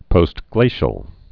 (pōst-glāshəl)